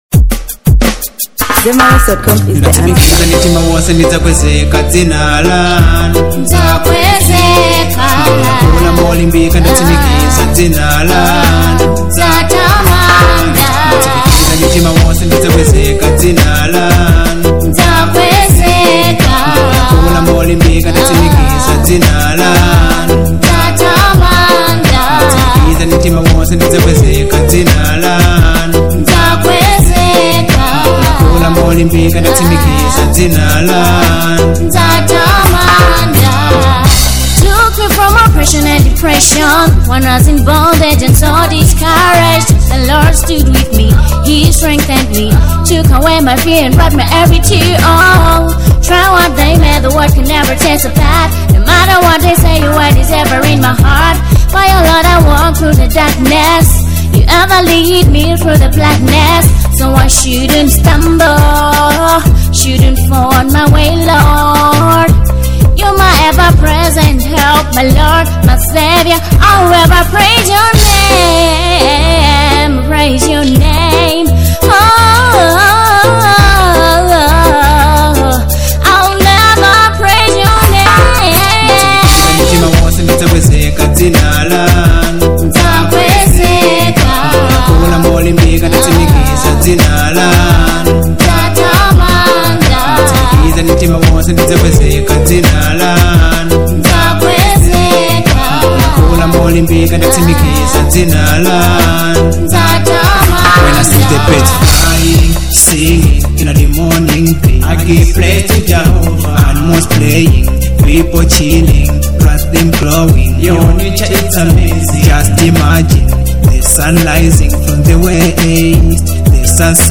Dancehall • 2025-09-15